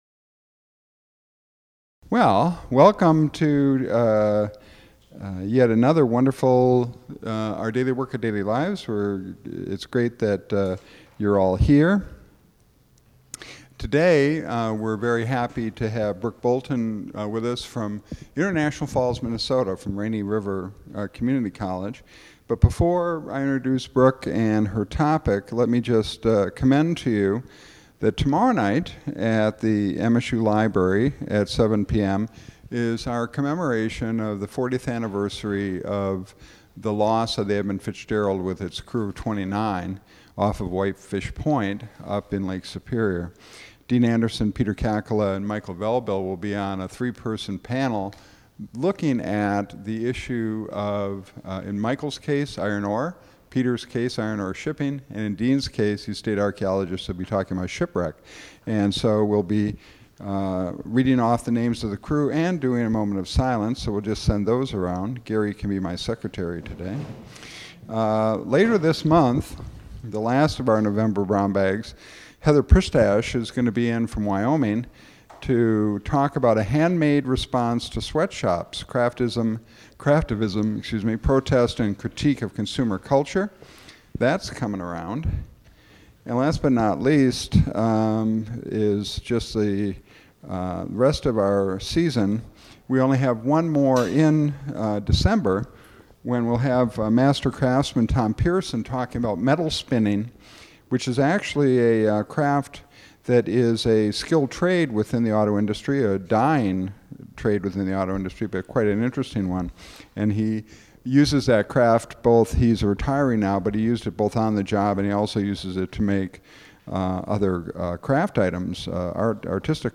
She also discusses the difficulties involved in translating Paivio's poetry from Finnish to English. The recording cuts out briefly just before the question and answer session and then resumes.
Held in the MSU Museum Auditorium.